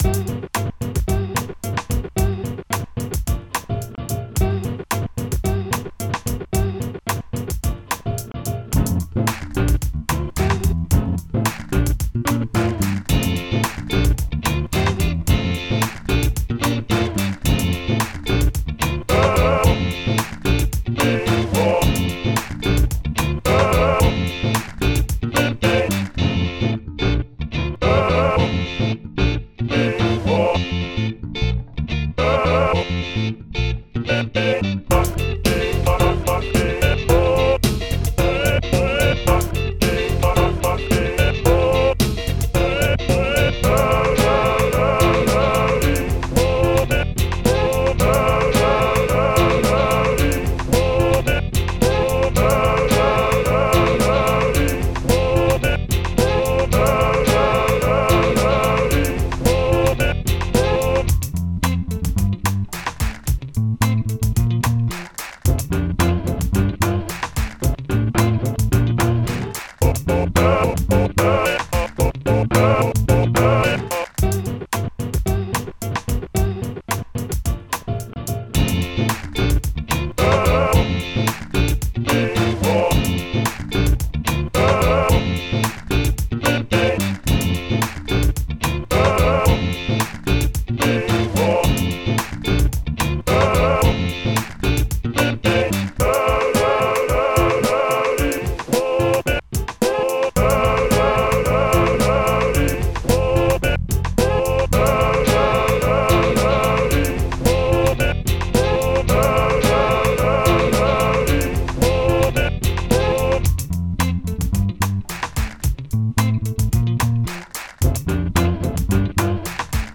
was sampled and played over an acoustic song